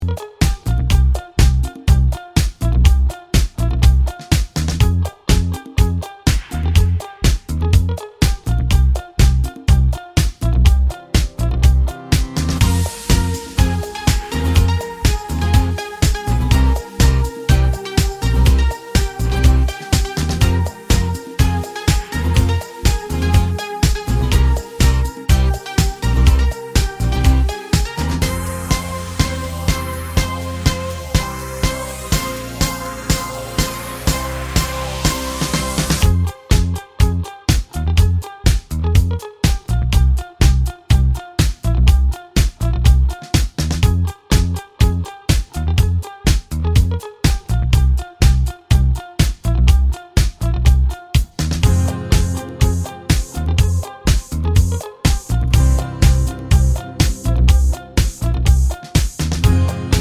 Medleys